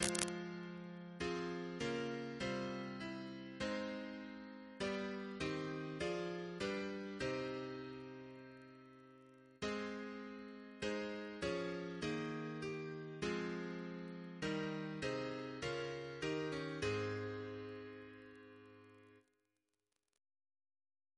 Double chant in A♭ Composer: Sir Herbert S. Oakeley (1830-1903), Professor of Music, Edinburgh Reference psalters: RSCM: 105